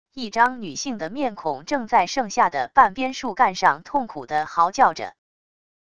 一张女性的面孔正在剩下的半边树干上痛苦地嚎叫着wav音频